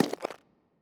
Click Back (8).wav